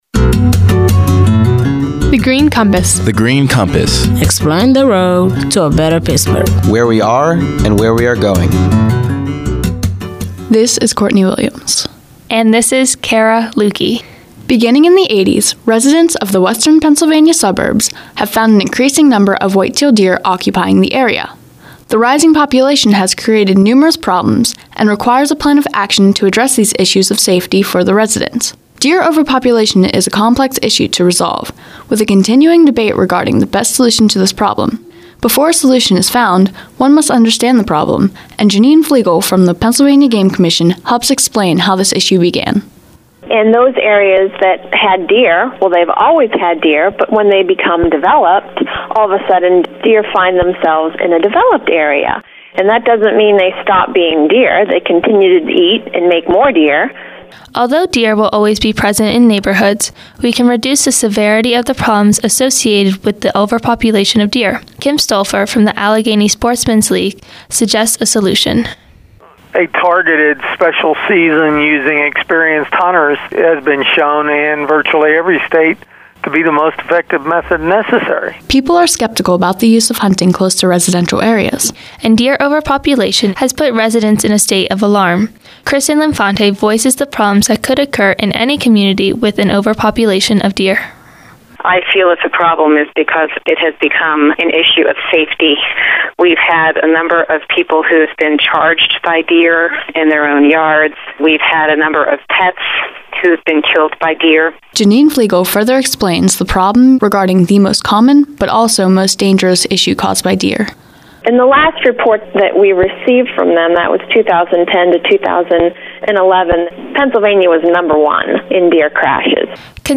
In July 2012, twenty-three students about to enter their first year of college created these nine radio features as Summer Youth Philanthropy Interns at The Heinz Endowments.
interview